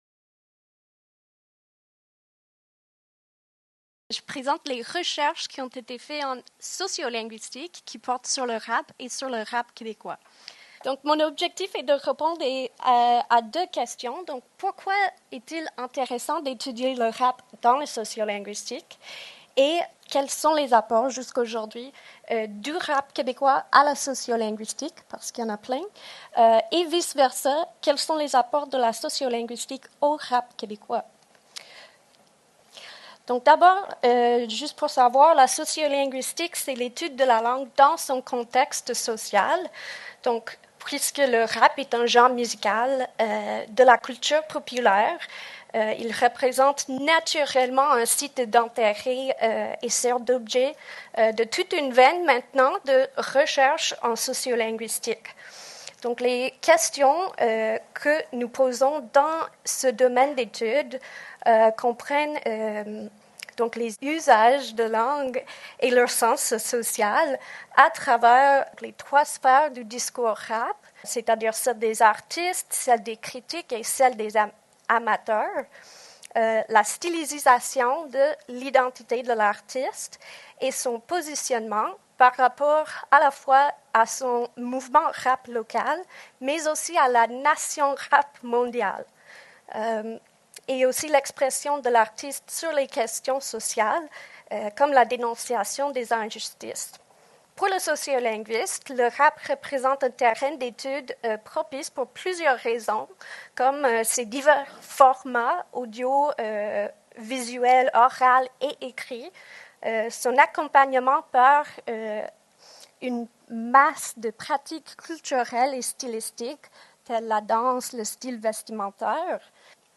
Conférence donnée lors de la demi-journée Rapper en 3 temps. Le volet Discours sur le rap réunissait des intervenants et intervenantes qui partageaient leurs connaissances, leurs savoirs et leurs parcours pour mieux saisir cet univers souvent réservé aux personnes initiées.